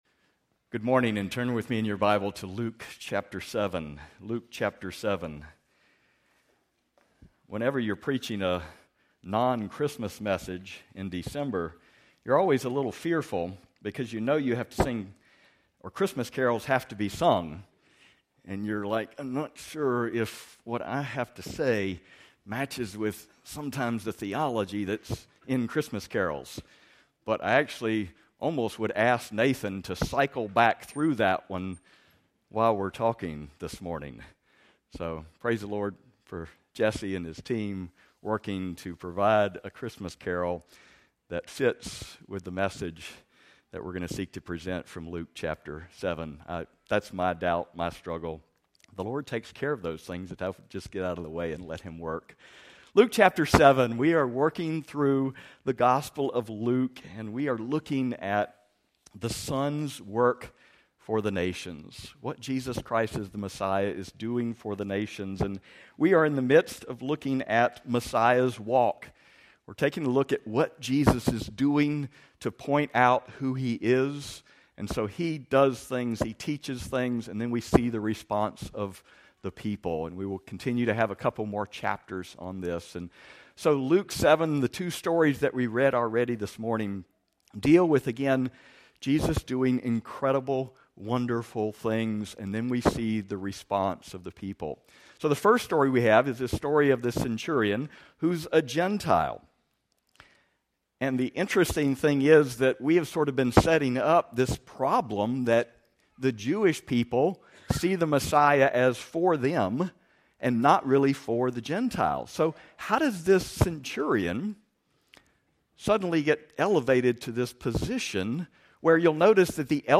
Sermons | Anchor Community Church